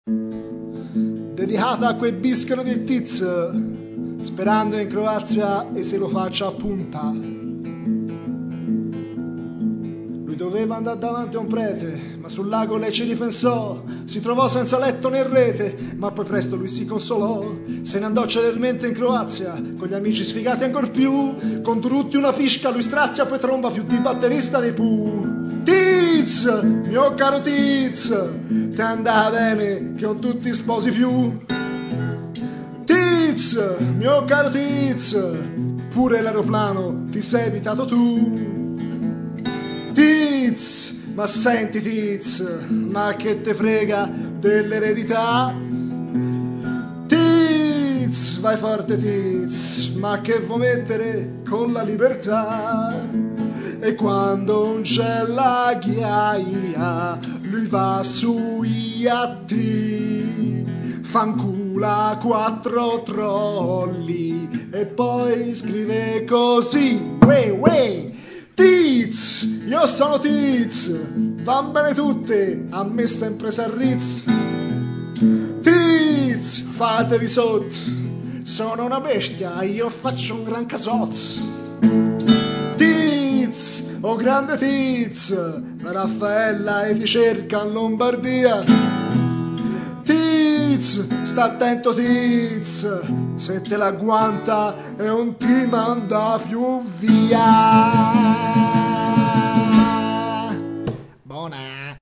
Vagamente malinconica pero'.